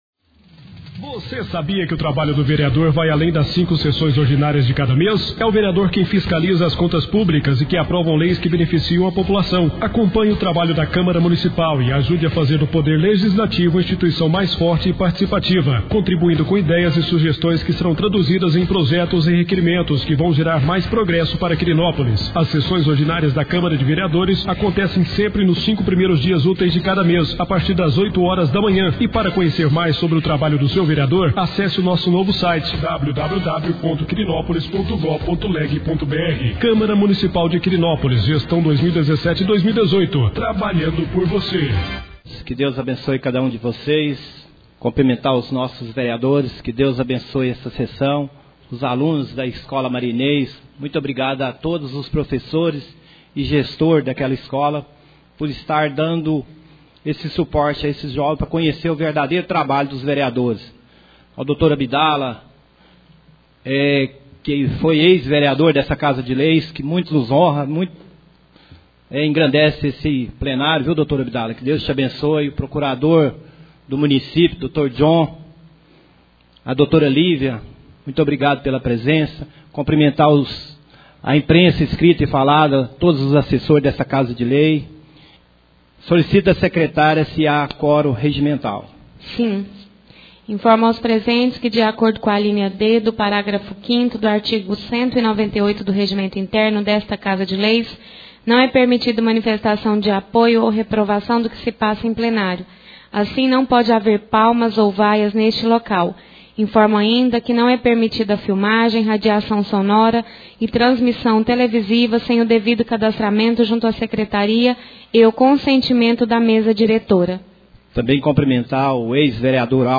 3ª Sessão ordinária do mês de Maio 2017